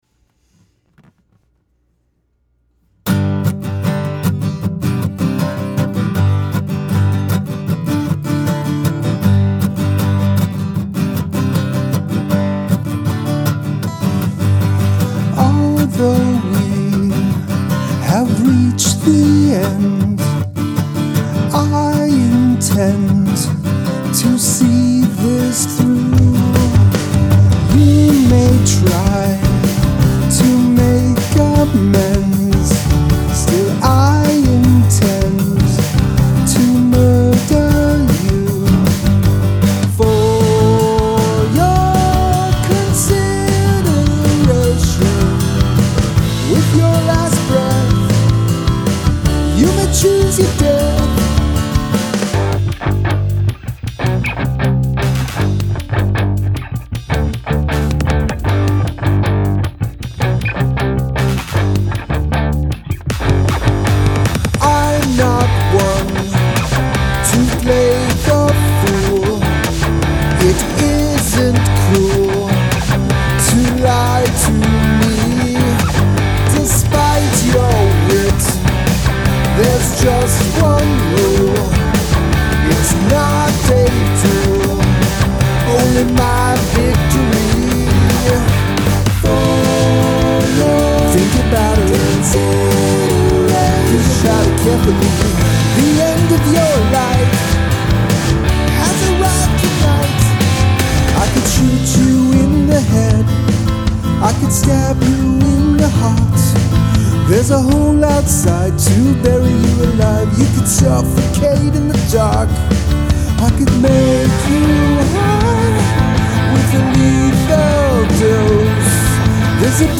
Acoustic guitars vs electric guitars
acoustic drums vs. electric drums